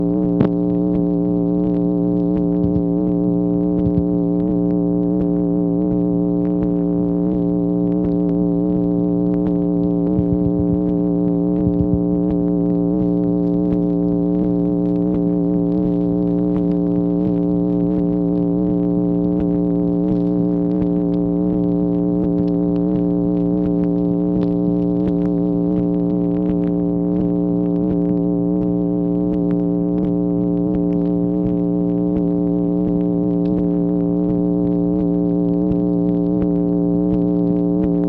MACHINE NOISE, September 28, 1965
Secret White House Tapes | Lyndon B. Johnson Presidency